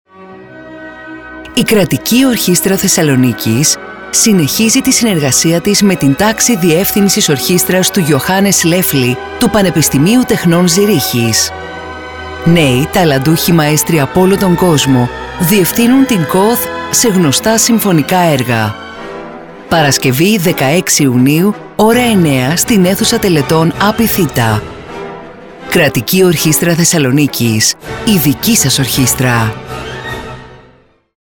Κατηγορία: Ραδιοφωνικά σποτ